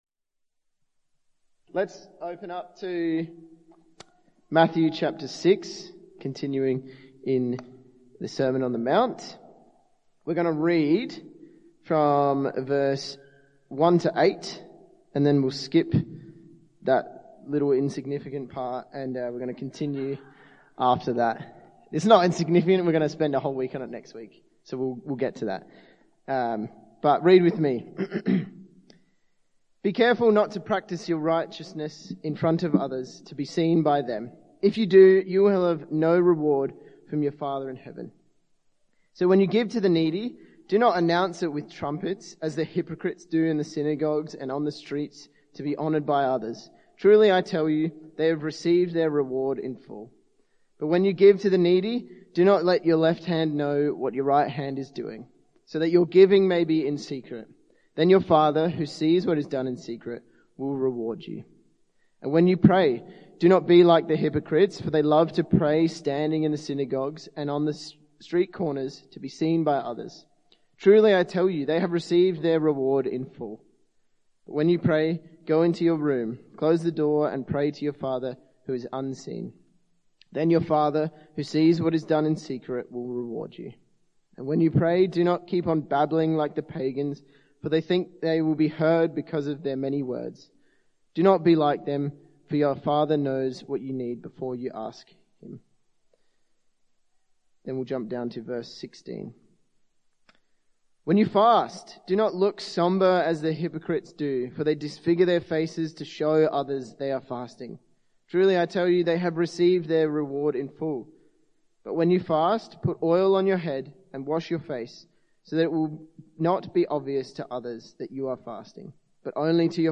In this Bible talk, we explore Jesus’ call to live out righteousness— not for the approval of others, but as a response to God’s grace. Looking at Matthew 6:1-18, we unpack the “why” behind spiritual habits like generosity, prayer, and fasting.